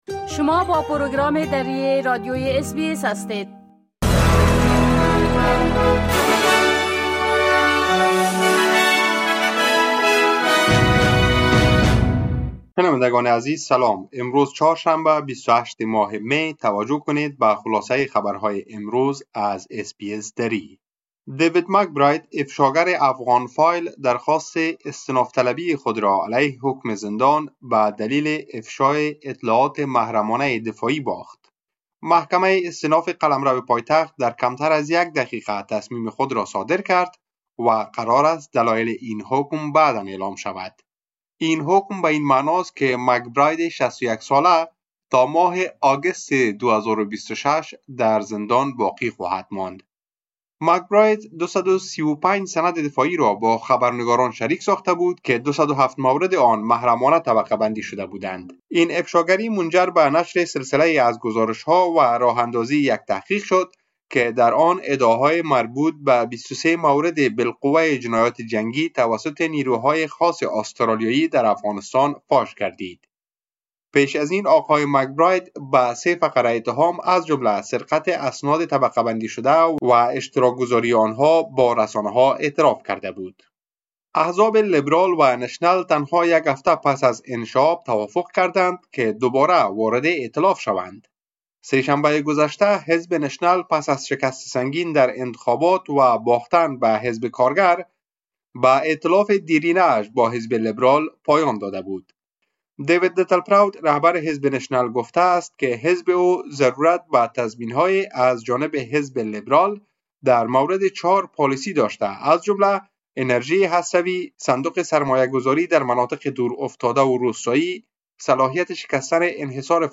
خلاصه مهمترين اخبار روز از بخش درى راديوى اس بى اس